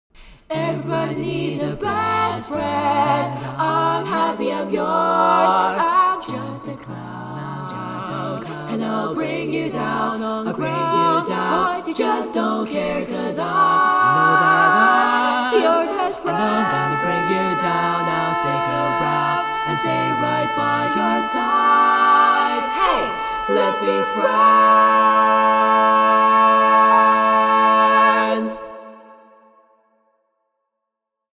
Key written in: A Major
How many parts: 4
Type: Barbershop
All Parts mix: